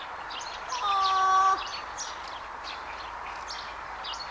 variksen kvaak-ääni
Siis ihan erilainen kuin se ääni joka muistutti
kauempaa kyläpöllösen ääntä.
variksen_kvaaak.mp3